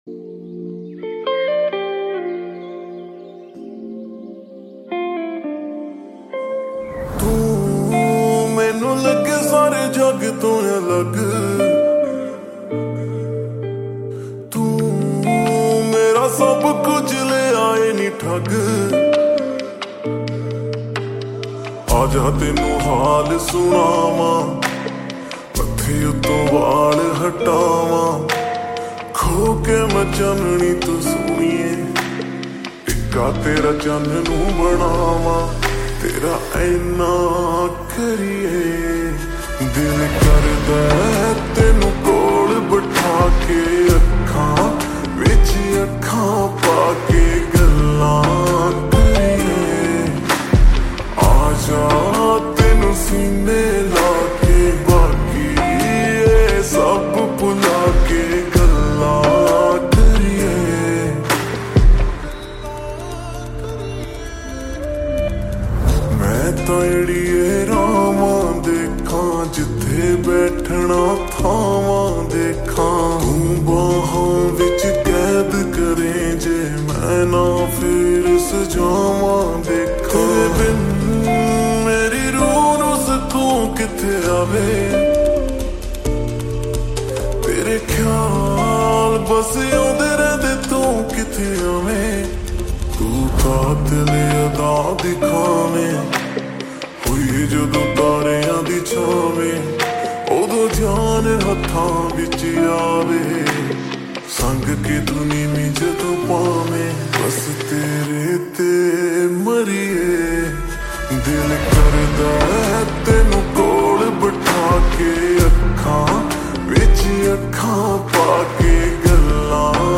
Full Song Slowed And Reverb
New Romantic Song